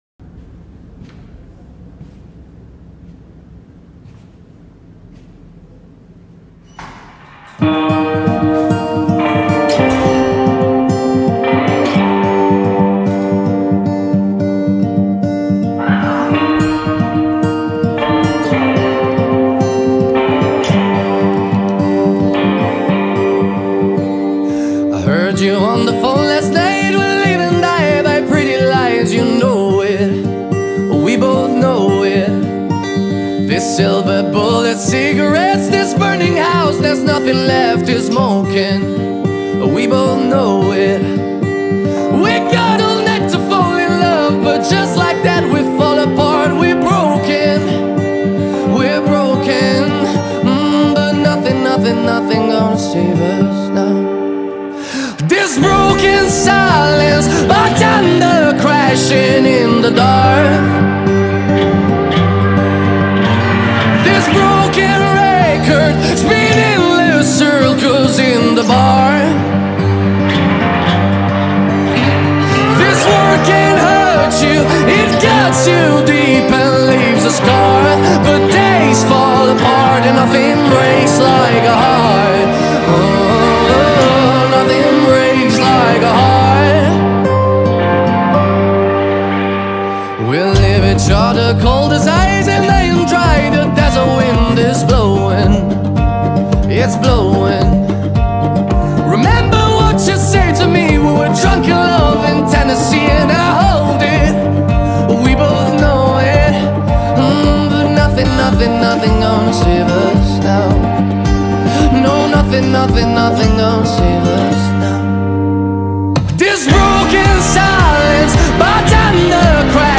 با صدای مرد
غمگین
غمگین خارجی